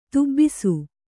♪ tubbisu